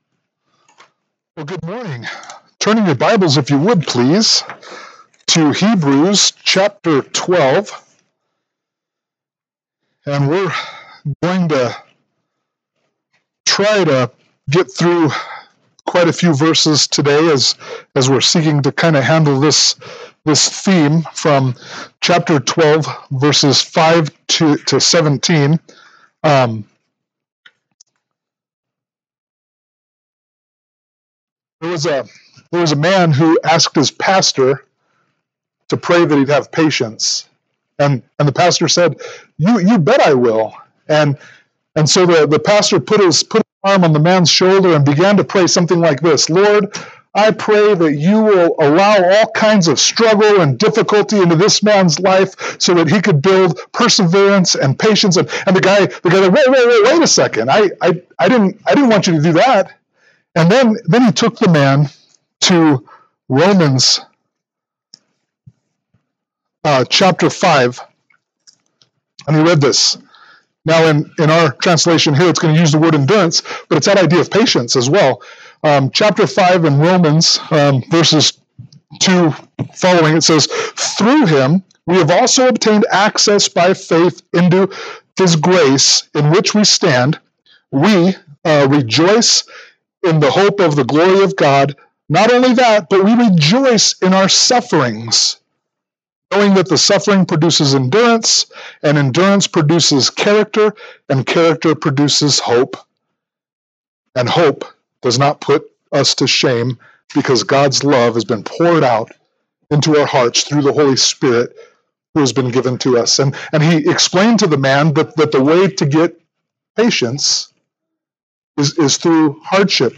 Hebrews 12:5-17 Service Type: Sunday Morning Worship « Hebrews 11:23-40 Hebrews 12:18-29